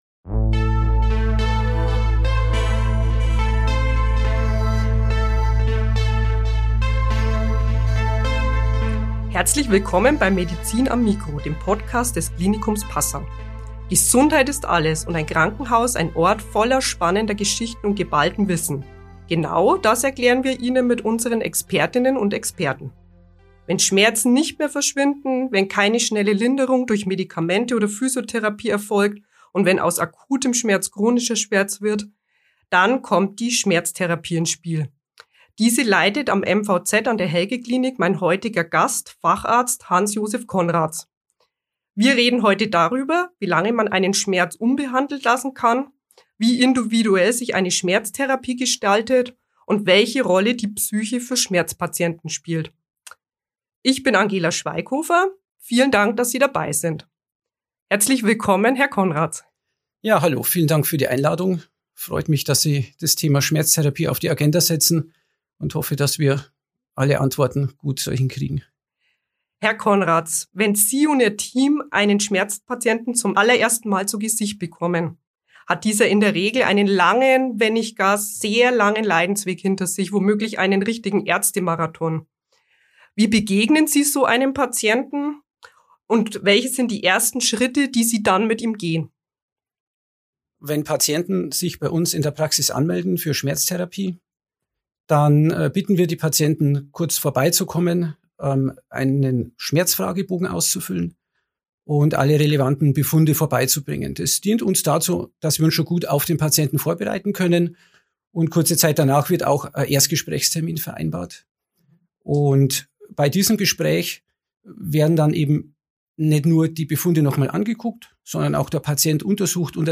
Unser Gast ist Schmerzspezialist